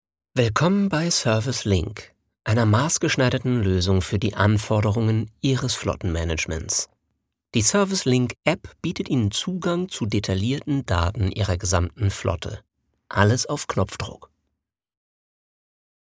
Stimmfarbe jung, dynamisch, natürlich, kernig, zart.
norddeutsch
Sprechprobe: eLearning (Muttersprache):
My voice sounds young, dynamic, naturalistic, tender.